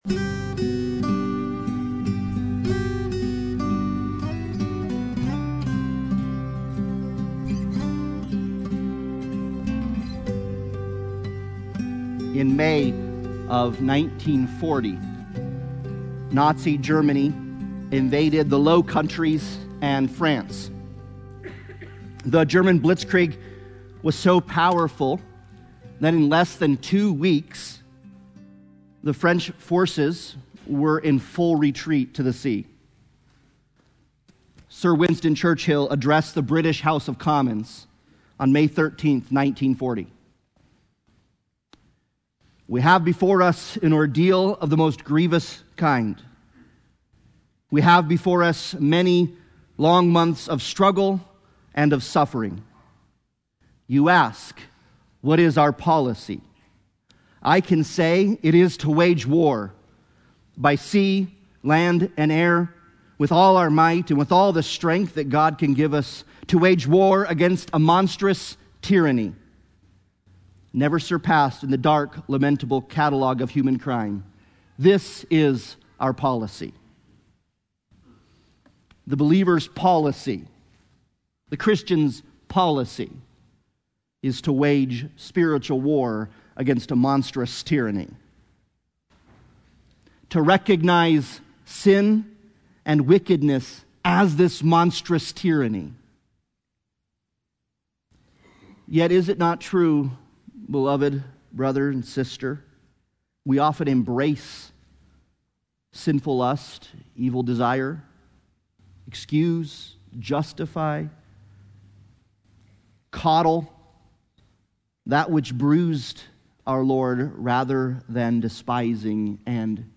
Life in Christ Passage: Ephesians 6:10-17 Service Type: Sunday Morning Worship « The Gospel According to Mark Stand